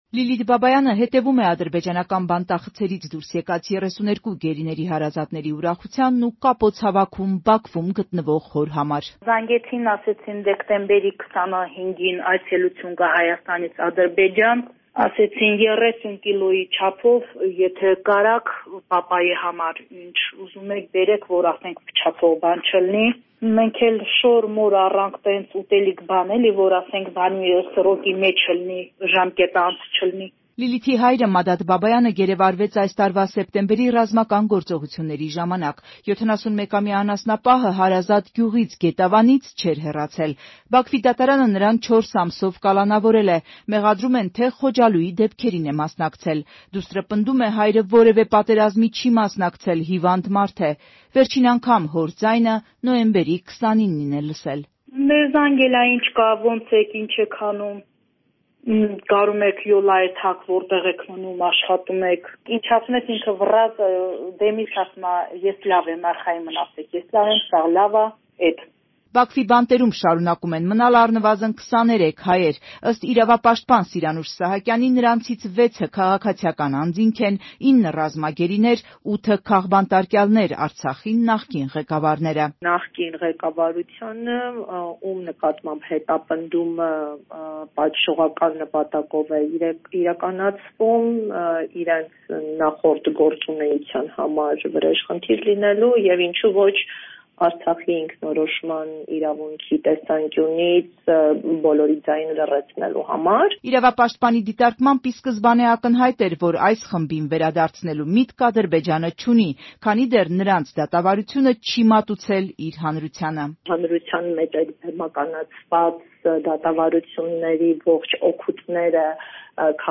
Մանրամասն «Ազատության» ռեպորտաժում.